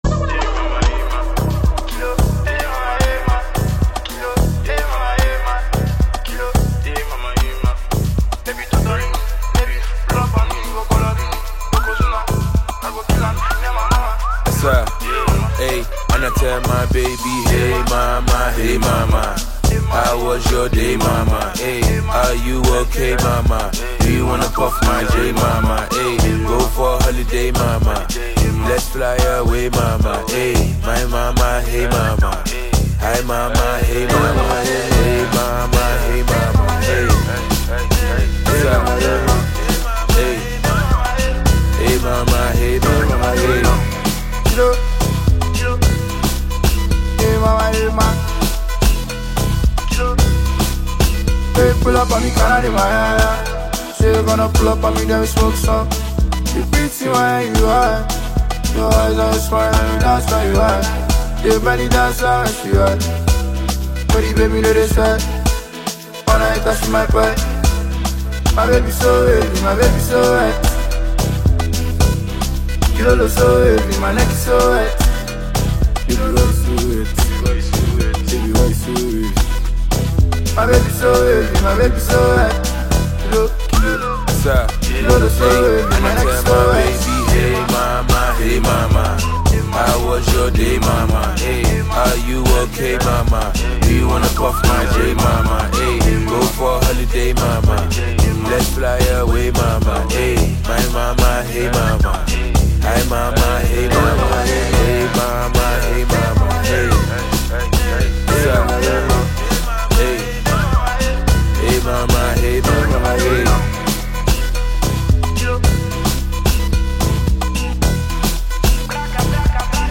Talented Nigerian singer and songwriter